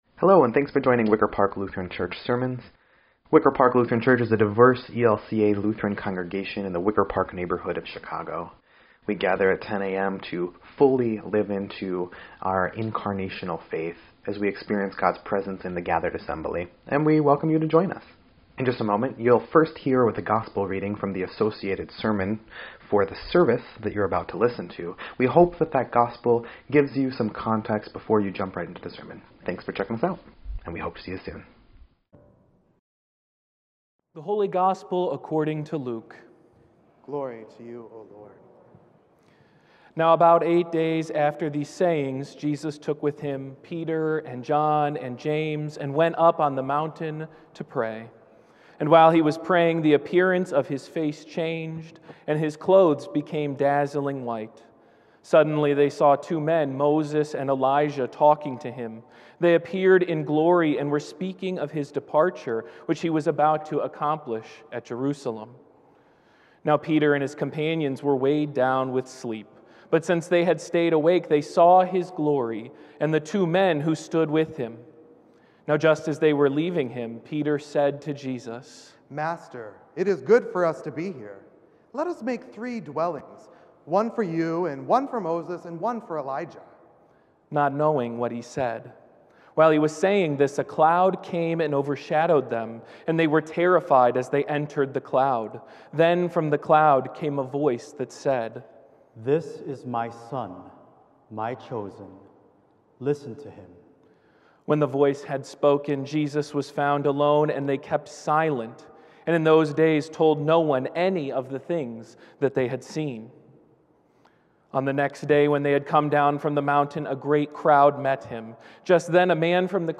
2.27.22-Sermon_EDIT.mp3